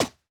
Player_Footstep_06.wav